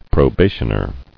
[pro·ba·tion·er]